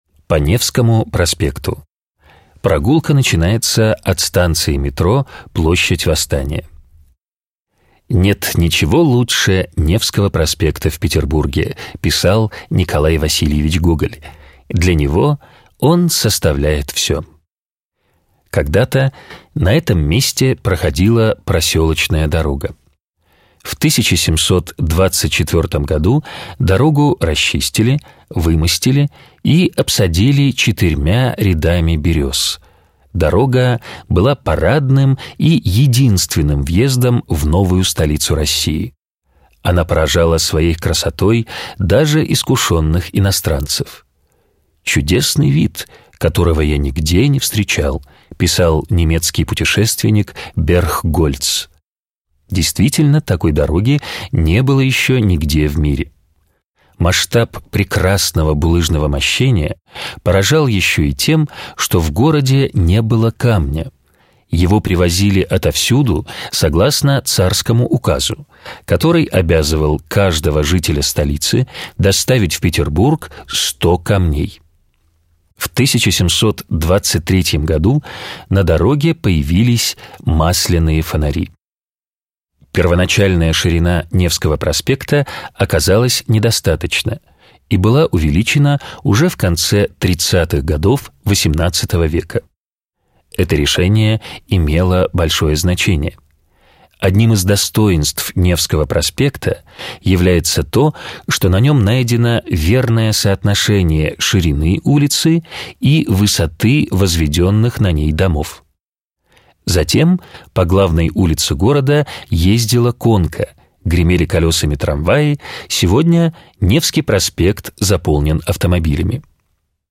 Аудиогид